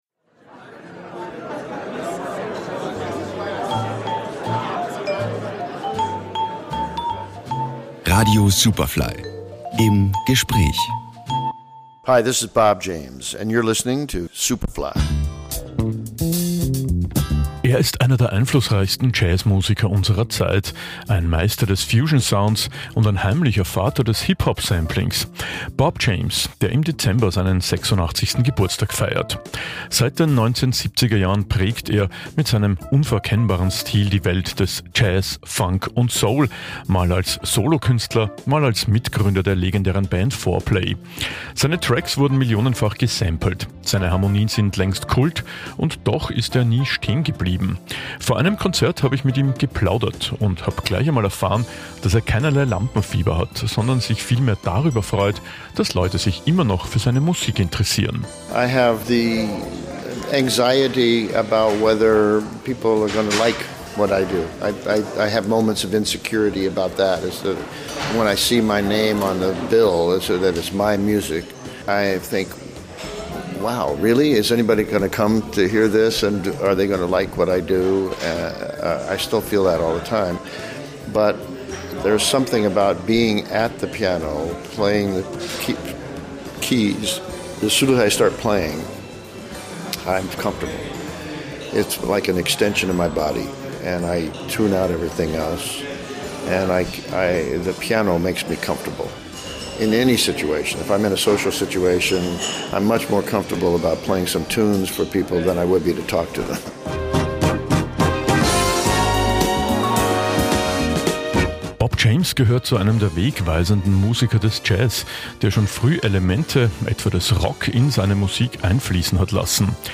Superfly Musikgeschichten | Interview w/ Bob James